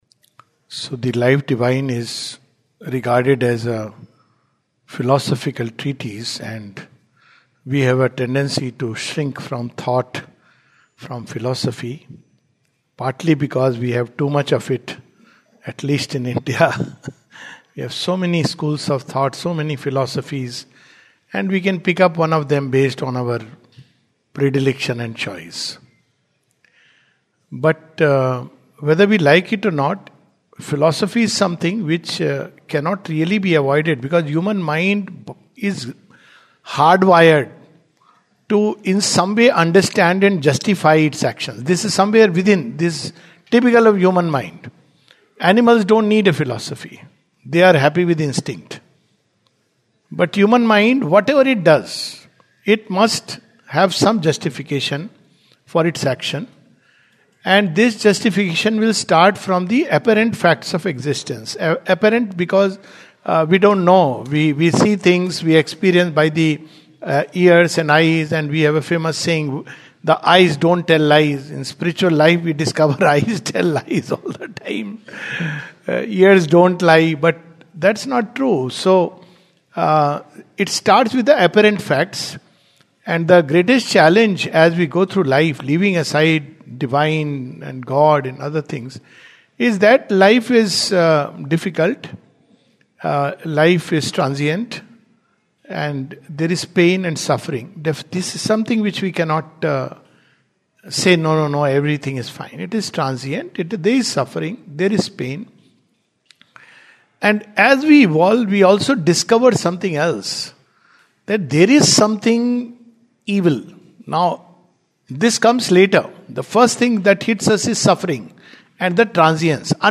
The Life Divine, 26th February 2026, Session # 06-09 at Sri Aurobindo Society, Pondicherry - 605002, India. The Divine and the Undivine (Part 5). This is the concluding talk on the Third chapter of Book Two part one of The Life Divine.